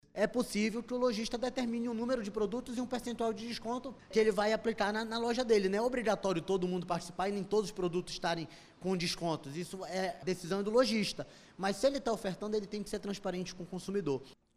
SONORA02_JALIL-FRAXE.mp3